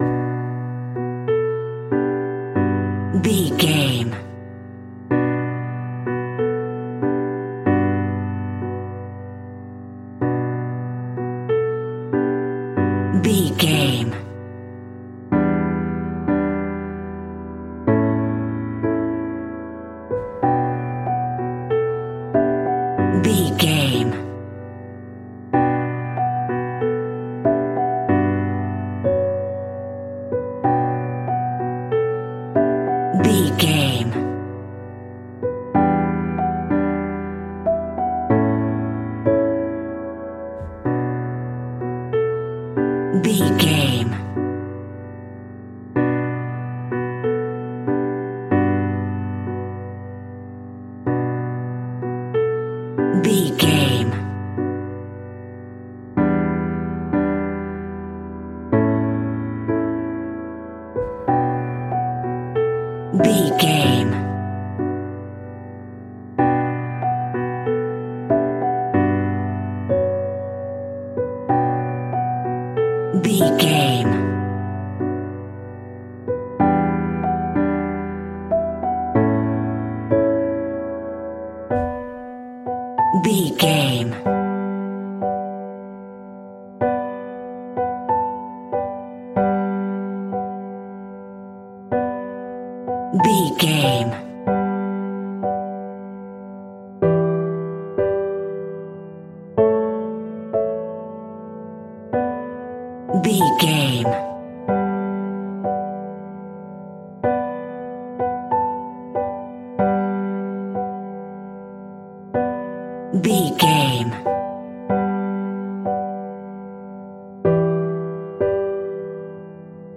Aeolian/Minor
Slow
tranquil
synthesiser
drum machine